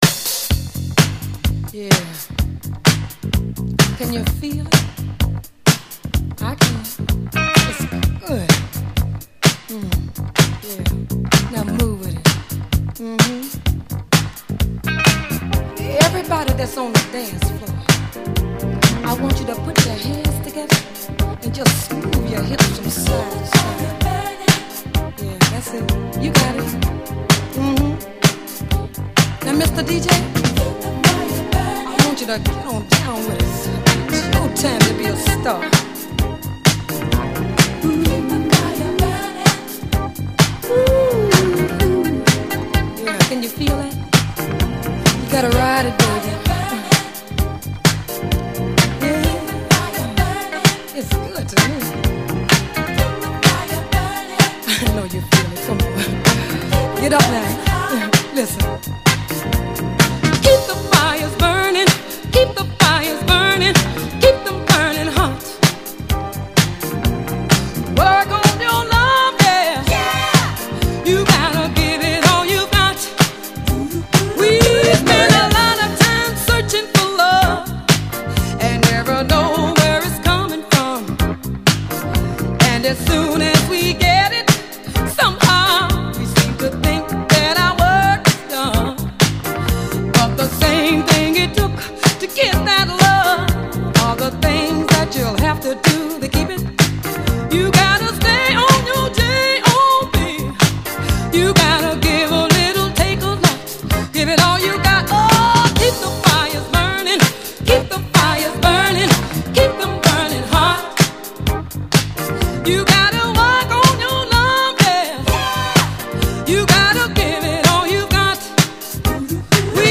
epic disco banger